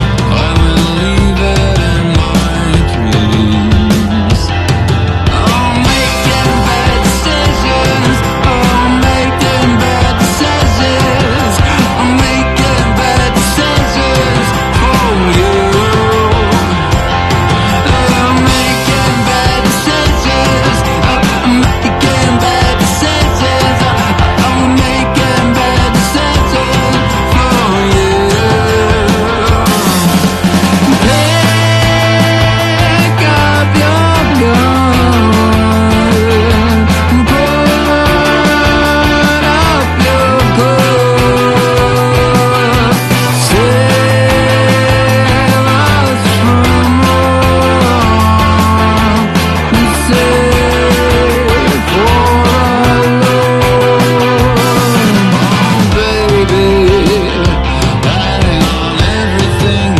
(WeTheFest 2023)